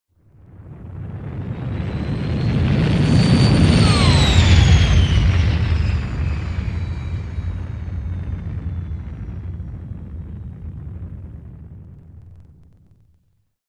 OtherLanding1.wav